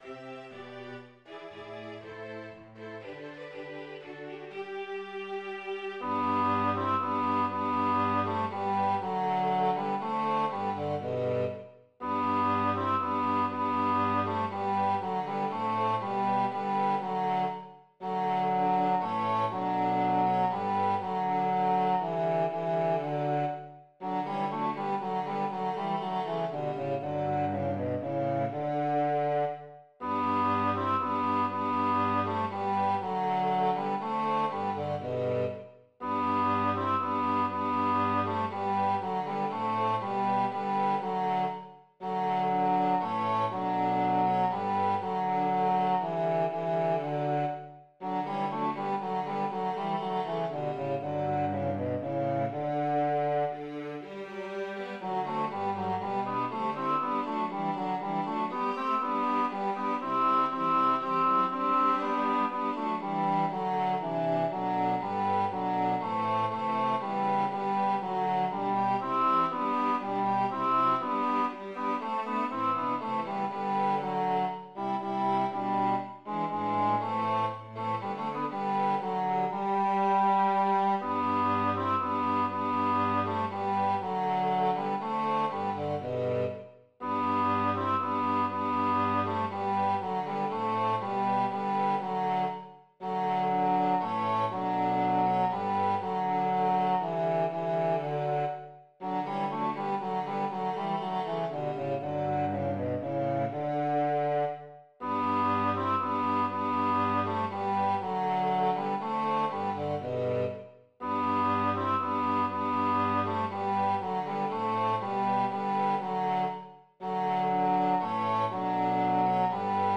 AdventWeihnachtenChormusikTenor2
Schneeberger Weihnachtslied Tenor 2 als Mp3